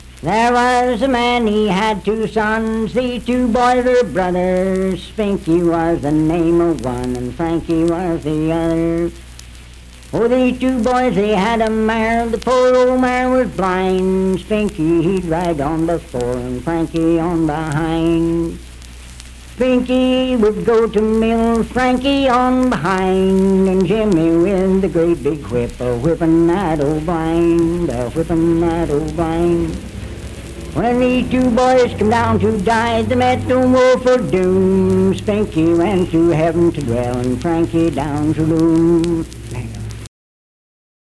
Unaccompanied vocal music
Verse-refrain 4(4).
Performed in Sandyville, Jackson County, WV.
Voice (sung)